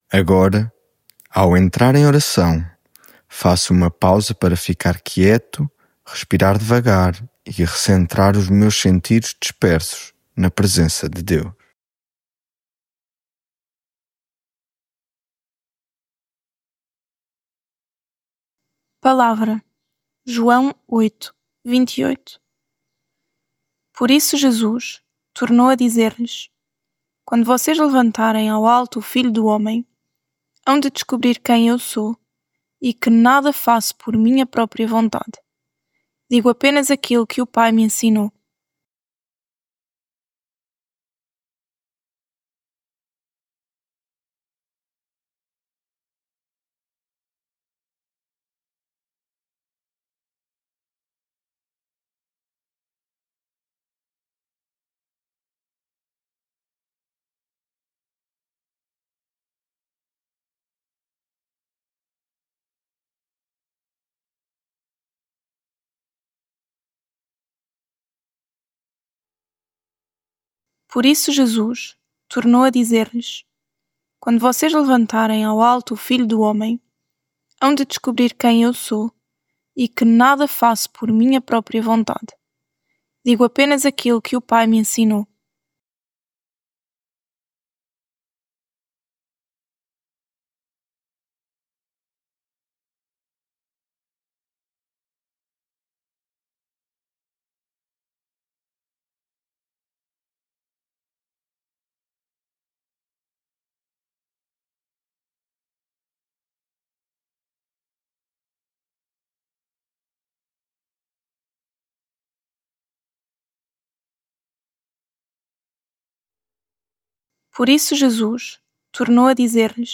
Devocional 35 Tu amas-me?